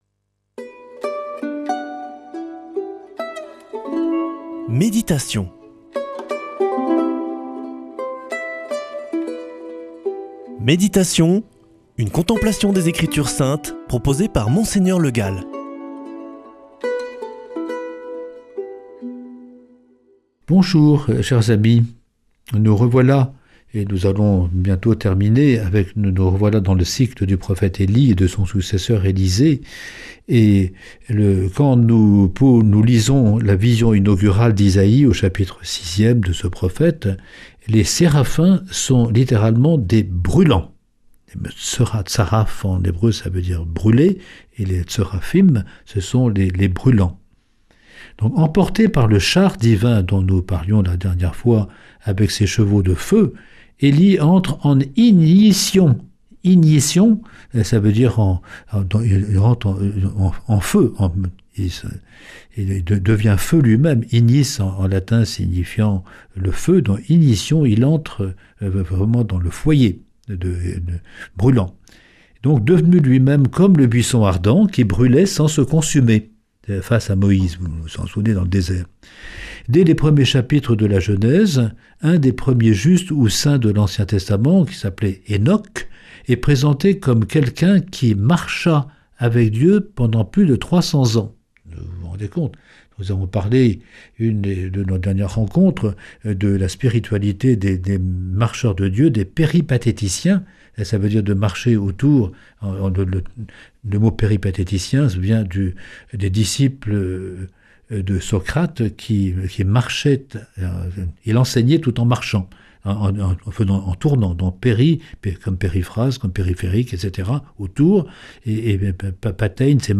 Méditation avec Mgr Le Gall
Monseigneur Le Gall
Présentateur